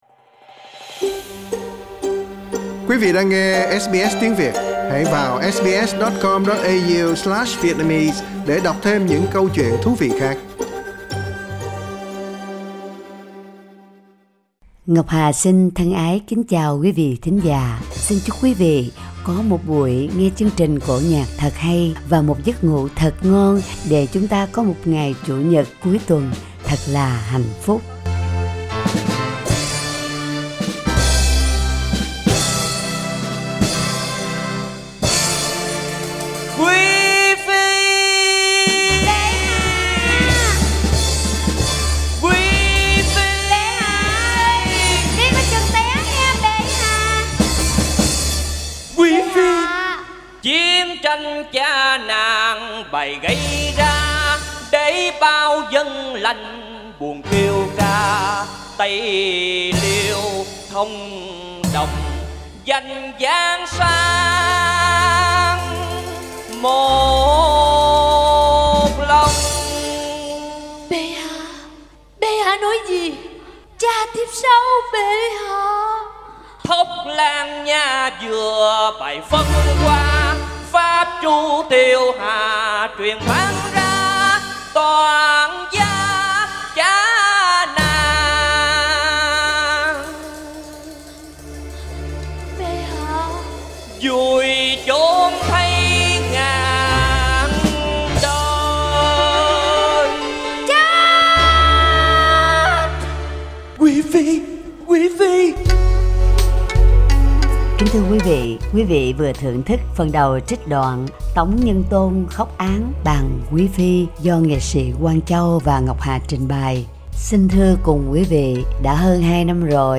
Giọng Ca Vọng Cổ: Tuồng cải lương Xử án Bàng Quý Phi
Kính mời quý vị cùng nghe phần 1 trích đoạn Cải Lương 'Tống nhân Tôn khóc án Bàng quí Phi'